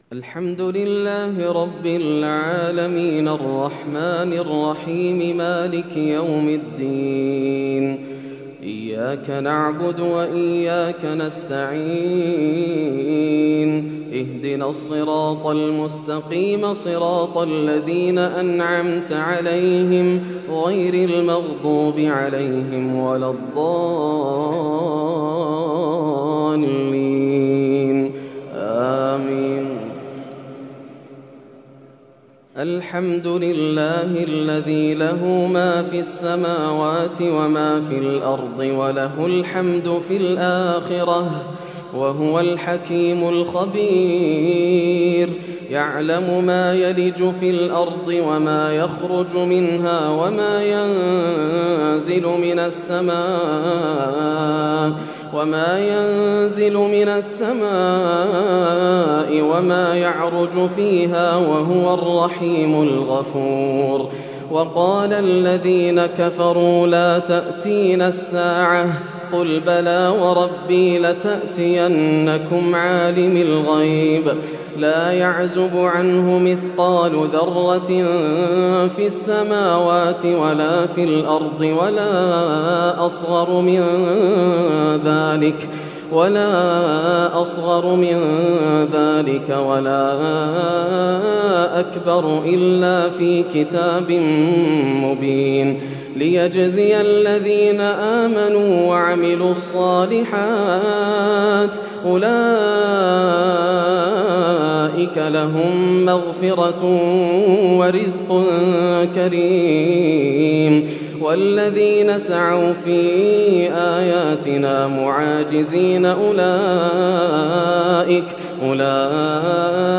صلاة التراويح من سورة سبأ حتى سورة الزمر للشيخ ياسر الدوسري | ليلة ٢٥ رمضان ١٤٣١هـ > رمضان 1431هـ > مزامير الفرقان > المزيد - تلاوات الحرمين